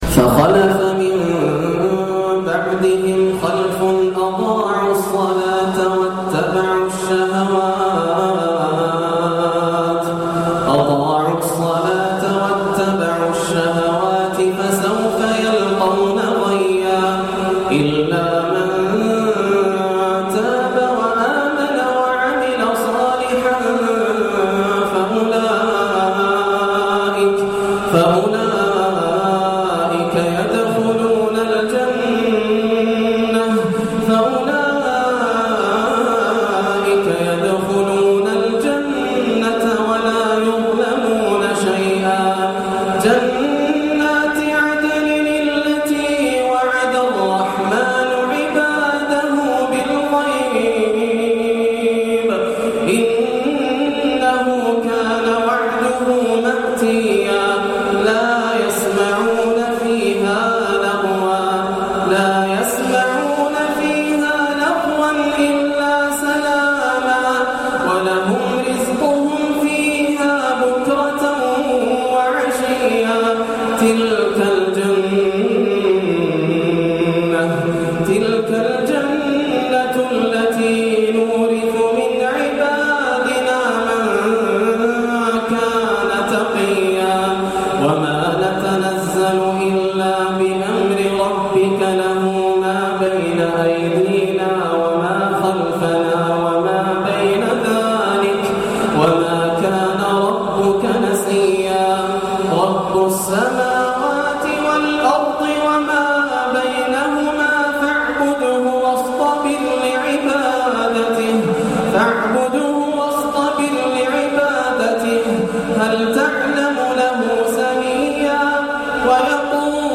أواخر سورة مريم من نوادر التلاوات للشيخ ياسر الدوسري من عام 1431هـ